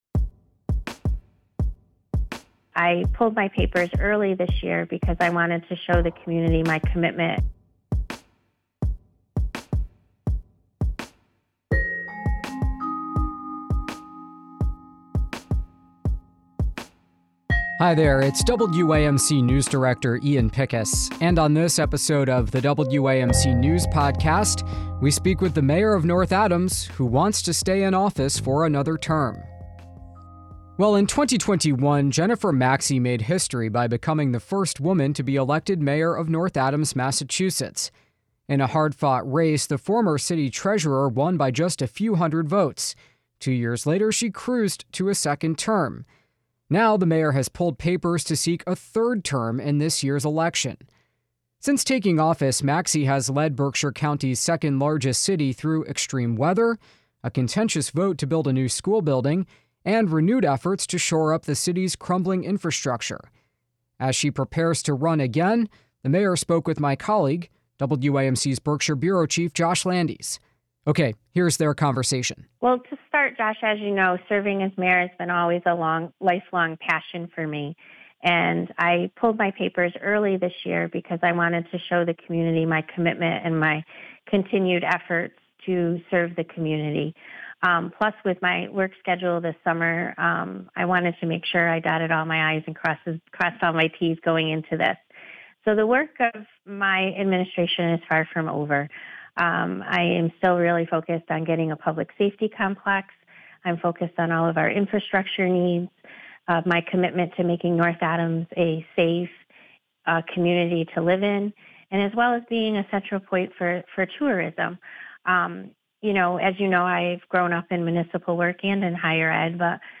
We speak with North Adams, Mass. Mayor Jennifer Mackey, who will seek a third term.